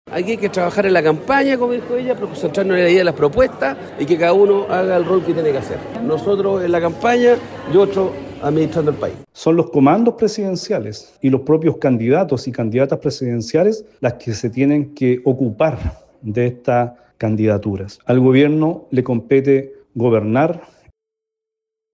En ese sentido, el senador Ricardo Lagos Weber, vocero de la campaña, hizo un llamado a que cada quien cumpla el rol que le corresponda, mientras que el diputado Eric Aedo llamó al Gobierno a gobernar.